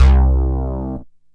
SYNTH LEADS-1 0003.wav